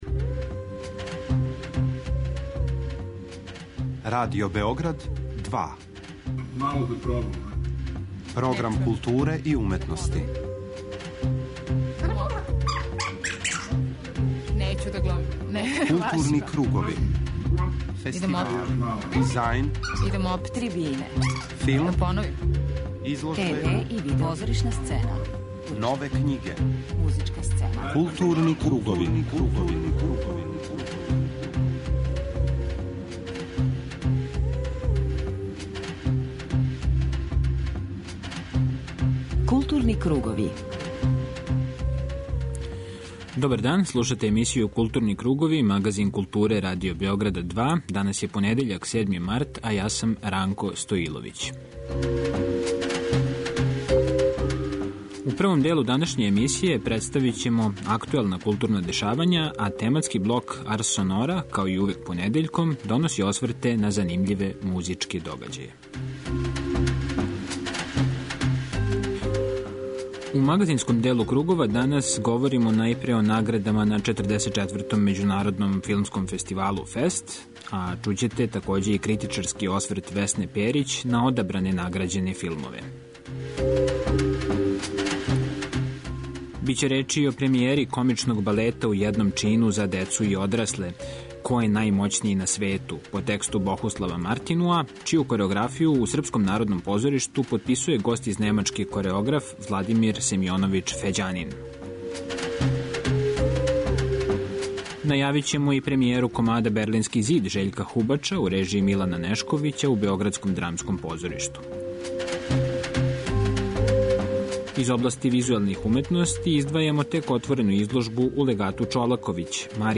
преузми : 40.25 MB Културни кругови Autor: Група аутора Централна културно-уметничка емисија Радио Београда 2.